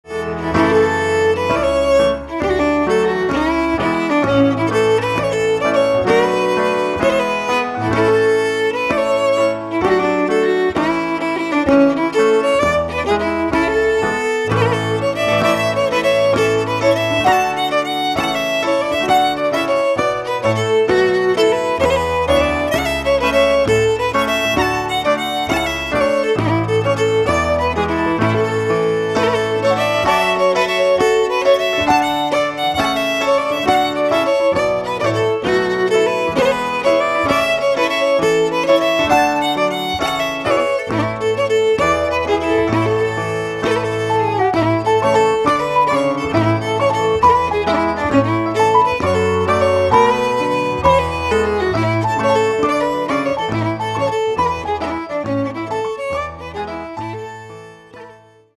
Lots of variety between listening and dance music.